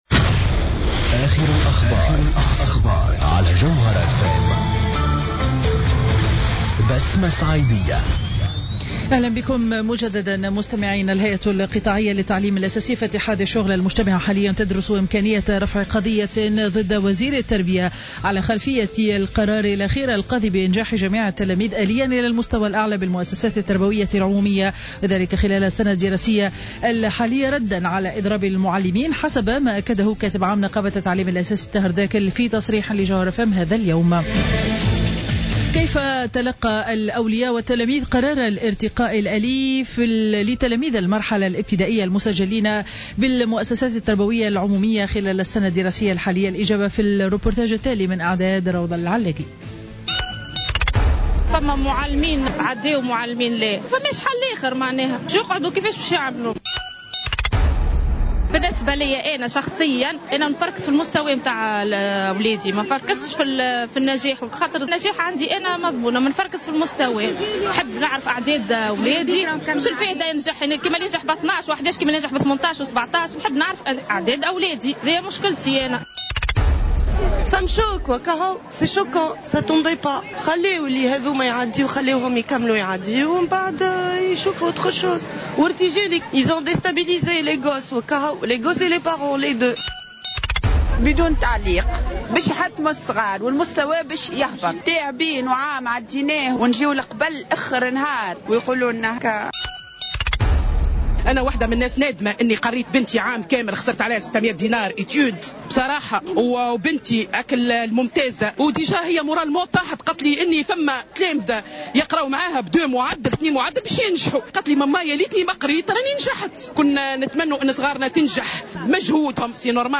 نشرة أخبار منصف النهار ليوم الجمعة 12 جوان 2015